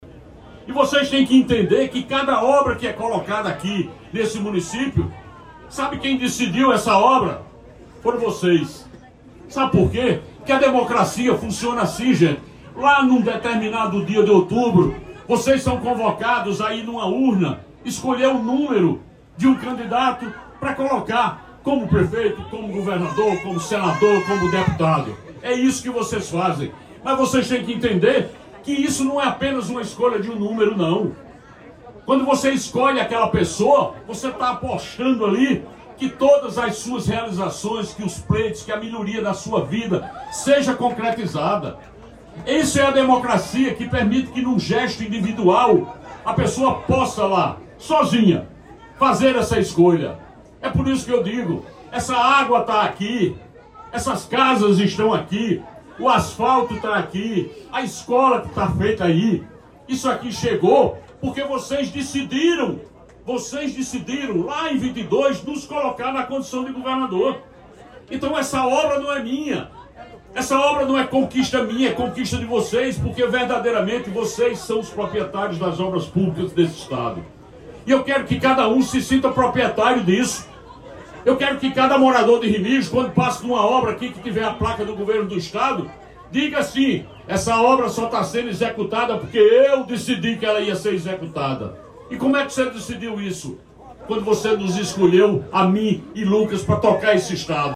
Na comunidade de Mãe Rainha, João Azevêdo, afirmou que as obras e serviços, são escolhas das comunidades e a executadas pelo   governo.
SONORA-GOVERNADOR-REMIGIO-JUNHO-25.mp3